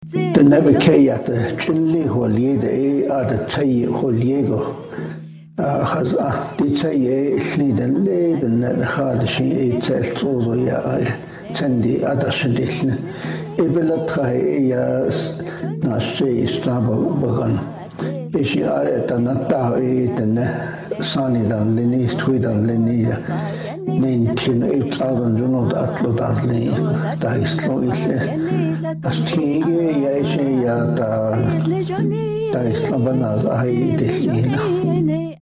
Here’s a recording of a story in a mystery language.